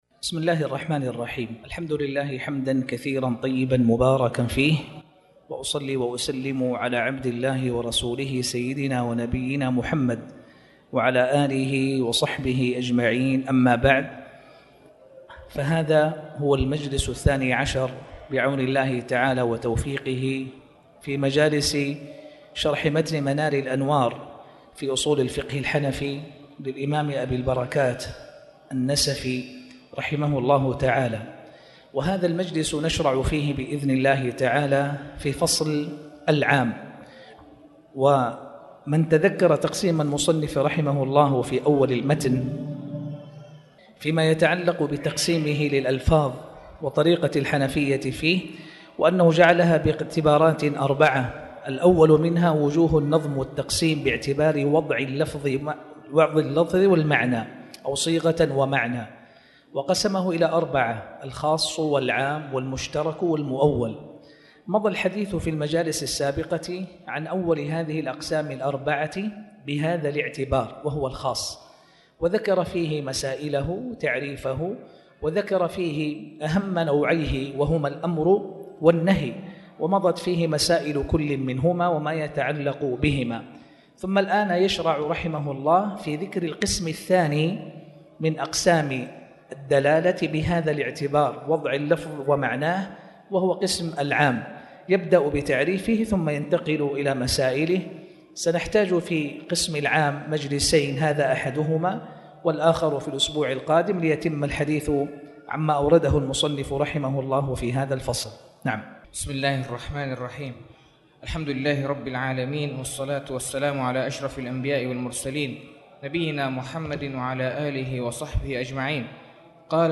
تاريخ النشر ٢ ربيع الثاني ١٤٣٩ هـ المكان: المسجد الحرام الشيخ